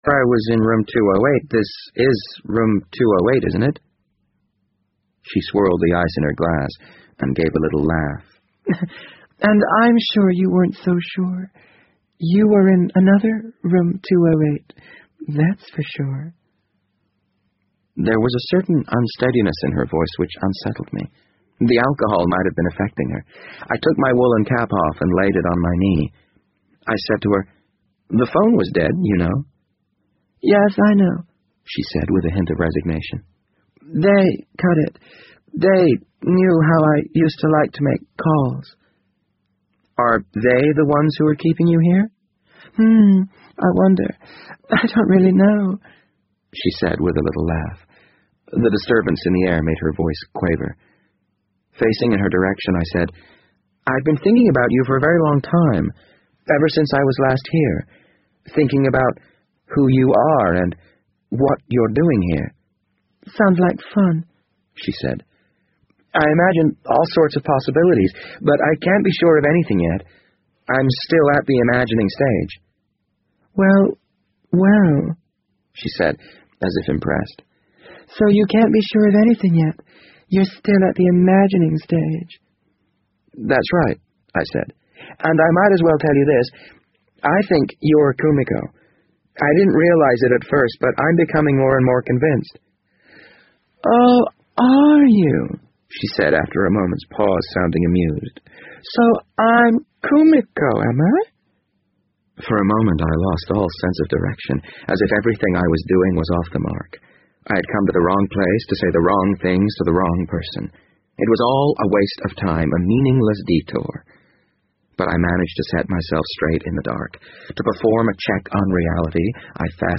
BBC英文广播剧在线听 The Wind Up Bird 015 - 5 听力文件下载—在线英语听力室